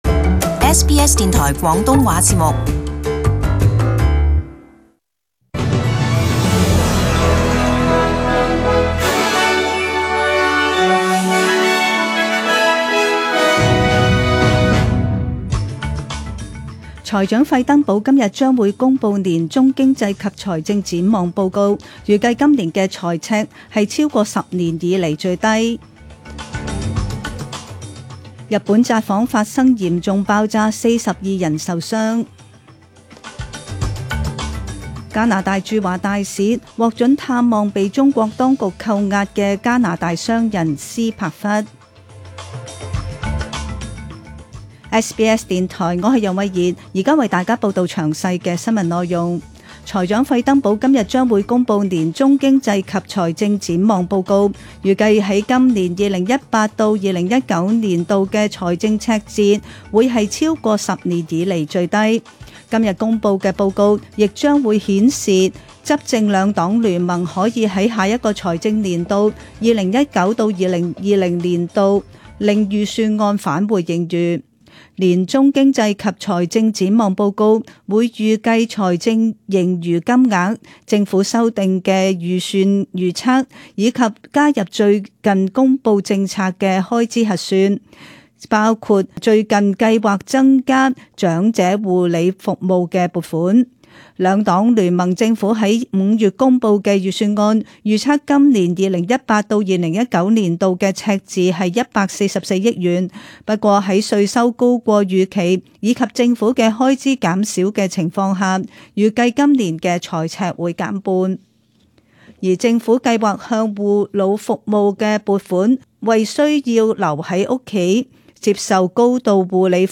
SBS中文新闻 （十二月十七日）
请收听本台为大家准备的详尽早晨新闻。